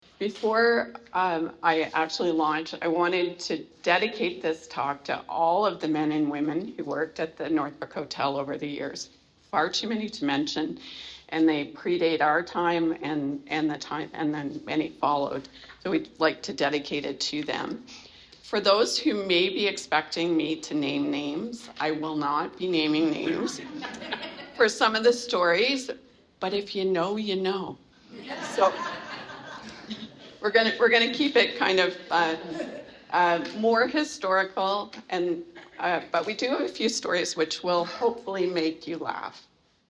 They searched through thousands of photos to create a fascinating story of the hotel, filled with anecdotes that made the audience laugh. The meeting was held at the Barrie Community Hall in Cloyne to an audience of about 65 people.